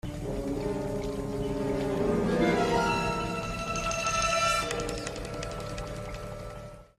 Play, download and share Predator Follow Alert original sound button!!!!
predator-follow-alert.mp3